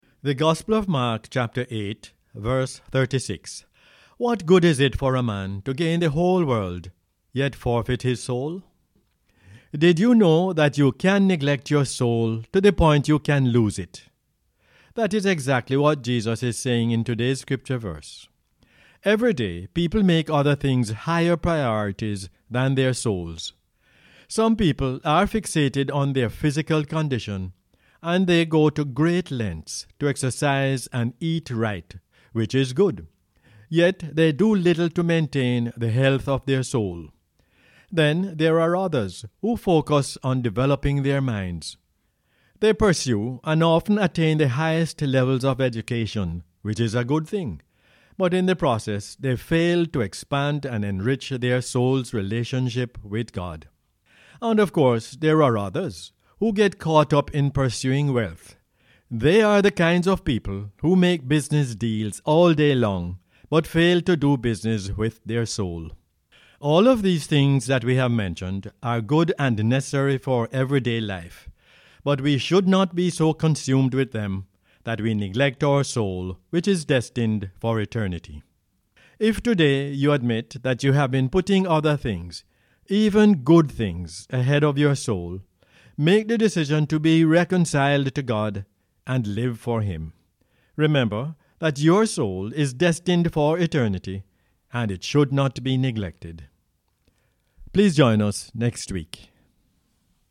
Mark 8:36 is the "Word For Jamaica" as aired on the radio on 11 March 2022.